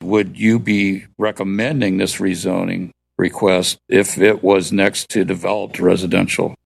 Council member Terry Urban wondered if that was a good idea.